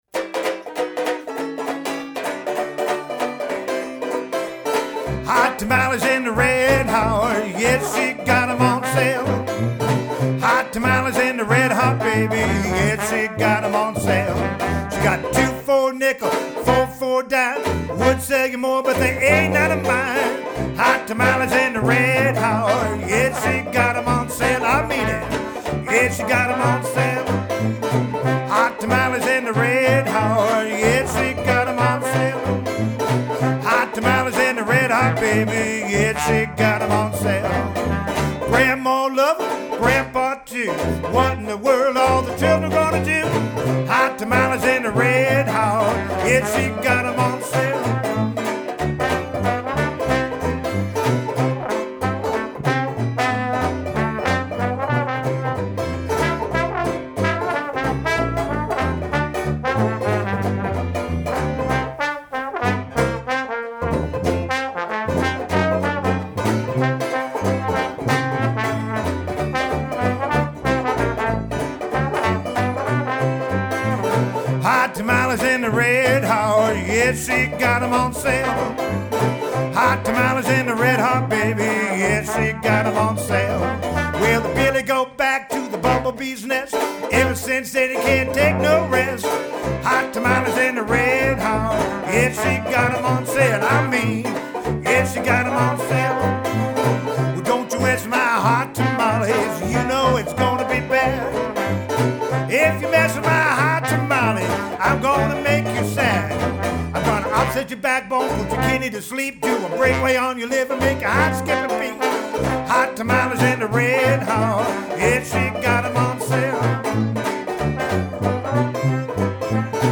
guitar, banjo & vocals
tuba
trombone